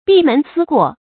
bì mén sī guò
闭门思过发音
成语正音思，不能读作“shī”。